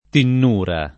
[ tinn 2 ra ]